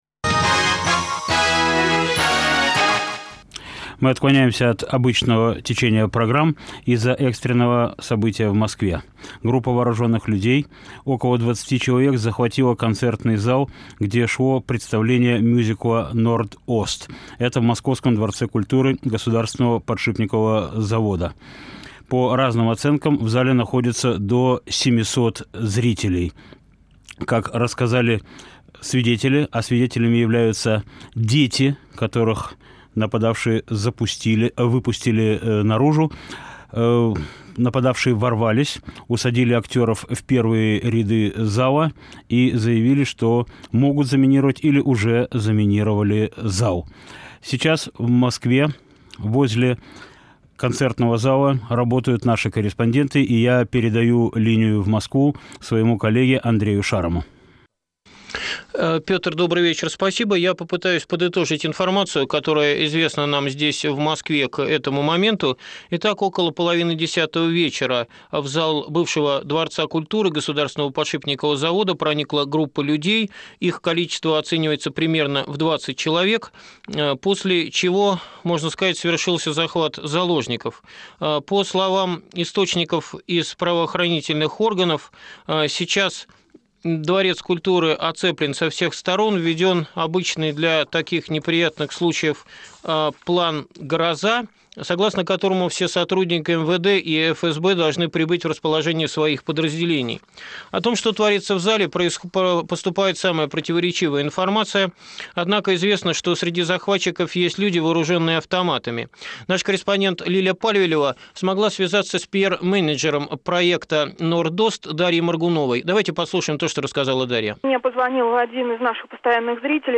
"Норд-Ост" - к 20-летию трагедии. Прямой эфир программы "Время Свободы", 2002 год. Ведущий Петр Вайль.